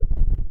Fakeout for A-90. (+ serves as ambiental noise for the Rooms)